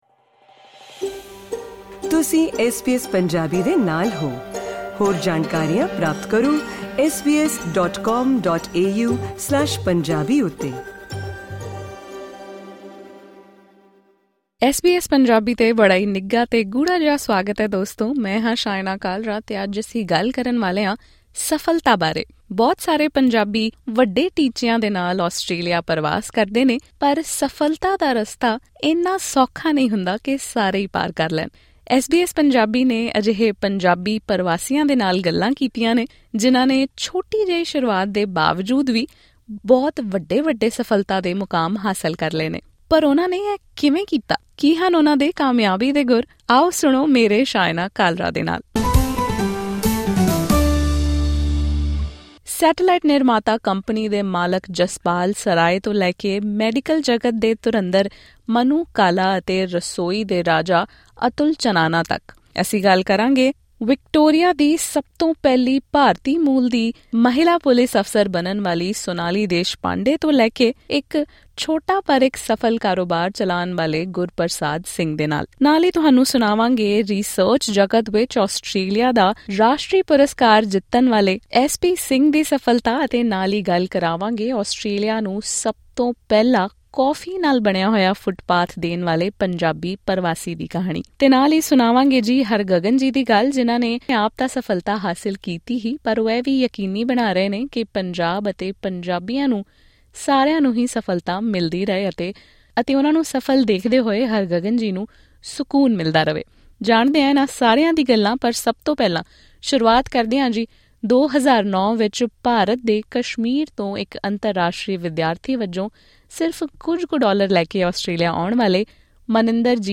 ਬਹੁਤ ਸਾਰੇ ਪੰਜਾਬੀ ਵੱਡੇ ਟੀਚਿਆਂ ਨਾਲ ਆਸਟ੍ਰੇਲੀਆ ਪਰਵਾਸ ਕਰਦੇ ਹਨ, ਪਰ ਸਫਲਤਾ ਦਾ ਰਸਤਾ ਇੰਨਾ ਸੌਖਾ ਨਹੀਂ ਹੁੰਦਾ। ਐਸ ਬੀ ਐਸ ਪੰਜਾਬੀ ਨੇ ਅਜਿਹੇ ਪੰਜਾਬੀ ਪ੍ਰਵਾਸੀਆਂ ਨਾਲ ਗੱਲ ਕੀਤੀ ਹੈ ਜਿਨ੍ਹਾਂ ਨੇ ਛੋਟੀ ਸ਼ੁਰੂਆਤ ਕੀਤੀ, ਪਰ ਅੱਜ ਉਹ ਆਸਟ੍ਰੇਲੀਆ ਦੇ ਸਫਲ ਕਾਰੋਬਾਰੀਆਂ ਵਿੱਚ ਸ਼ੁਮਾਰ ਹੁੰਦੇ ਹਨ। ਕੀ ਹਨ ਉਹਨਾਂ ਦੀ ਕਾਮਯਾਬੀ ਦੇ ਗੁਰ?